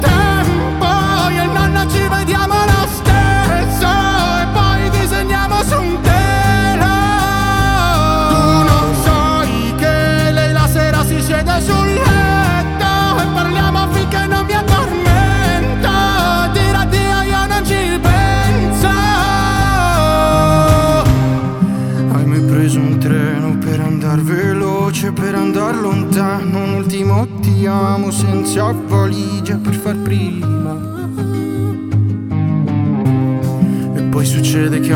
Pop Hip-Hop Rap
Жанр: Хип-Хоп / Рэп / Поп музыка